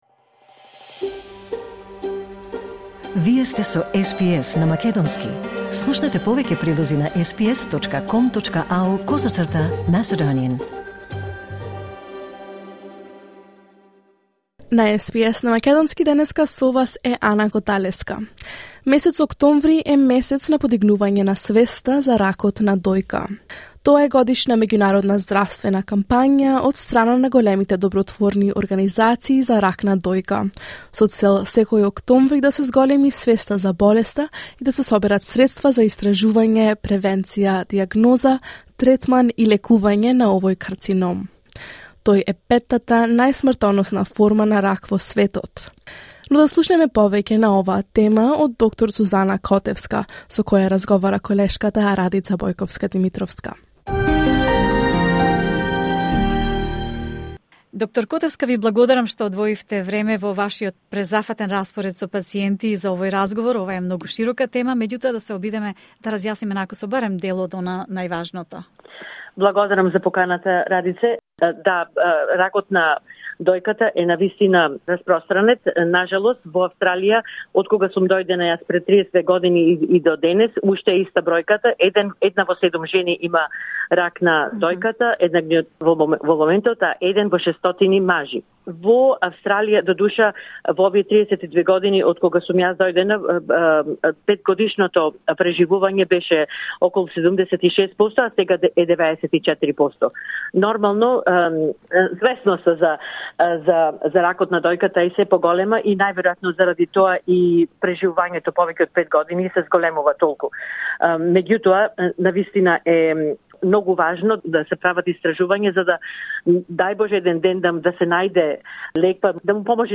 вели во разговор за СБС на македонски